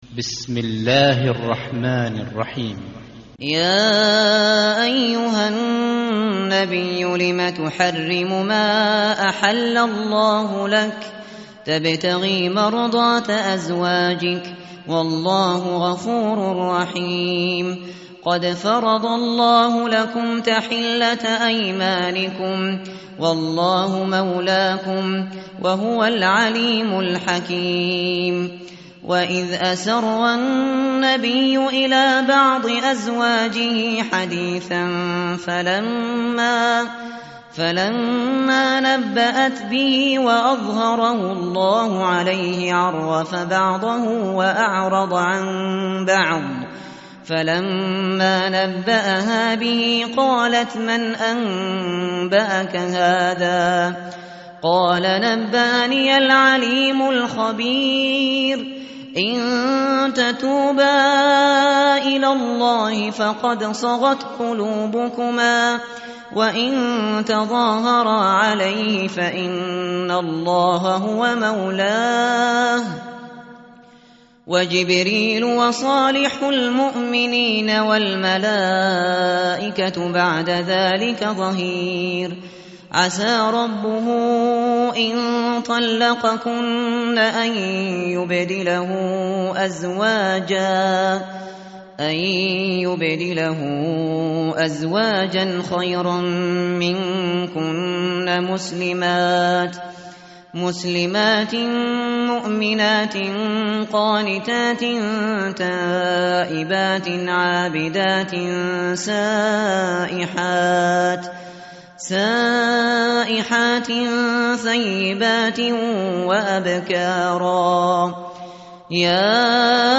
tartil_shateri_page_560.mp3